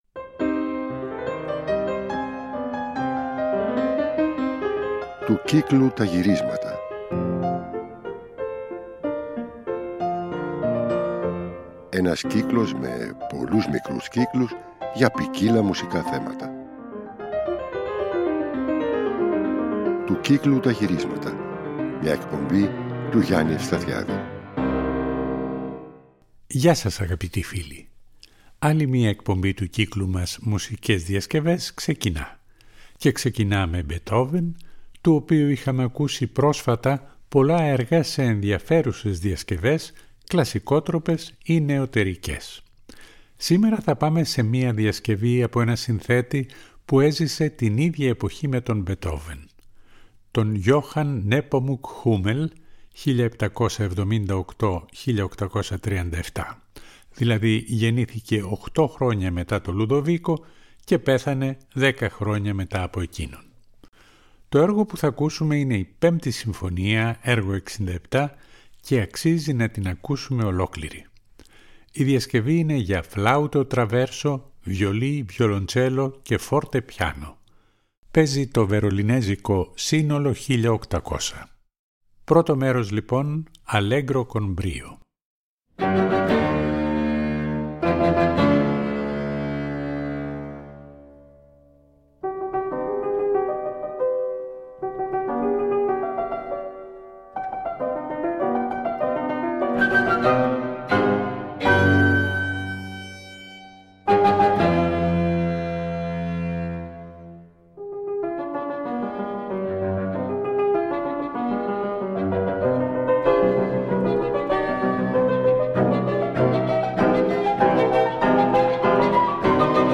κουβανέζικη ρυθμική διασκευή
για πιάνο, έγχορδα και ακορντεόν
ηχογραφημένα ζωντανά στην πλατεία Αγίου Μάρκου της Βενετίας